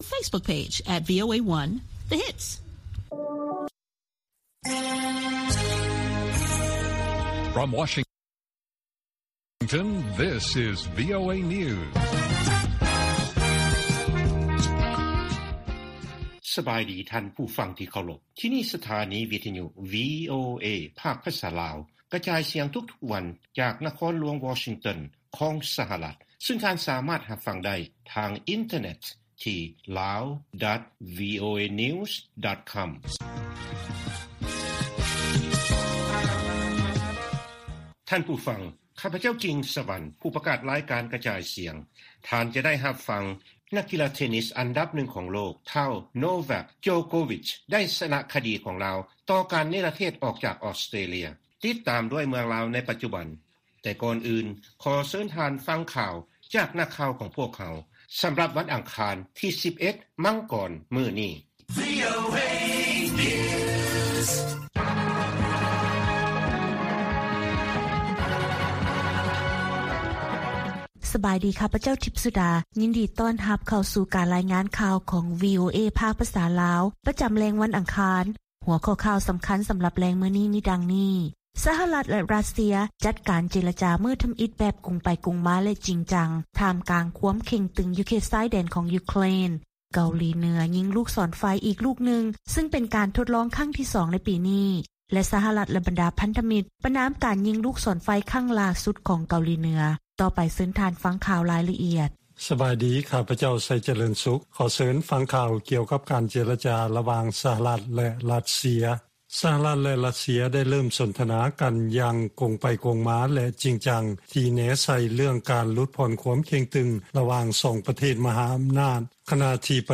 ວີໂອເອພາກພາສາລາວ ກະຈາຍສຽງທຸກໆວັນ, ຫົວຂໍ້ຂ່າວສໍາຄັນໃນມື້ນີ້ມີ: 1.